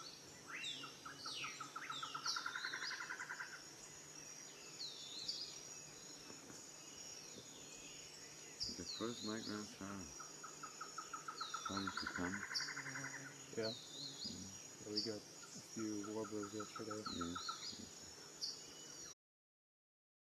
Campephilus guatemalensisPale-billed WoodpeckerPicamaderos PiquiclaroPic à bec clair